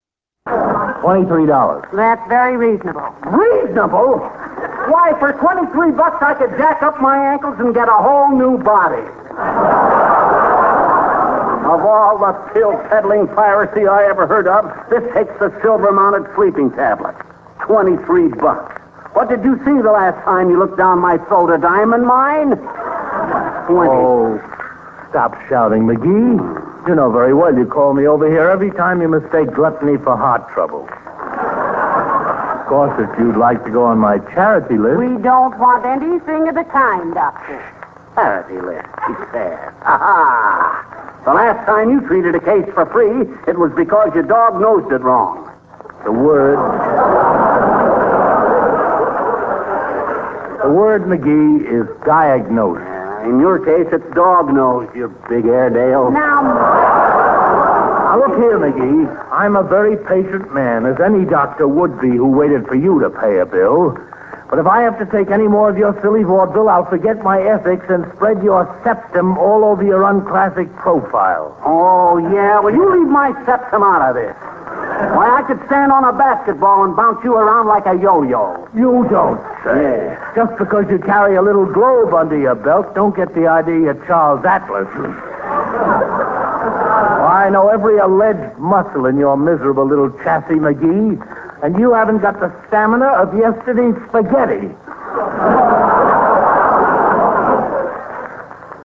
Arthur Q. Bryan as Doc Gamble
The Doctor was cast in a rather cynical mode, his favorite rejoinder to Fibber's latest flight of fancy being a weary "Take off your shirt" (since any burst of pep and energy from Fibber was considered to be a sign of illness).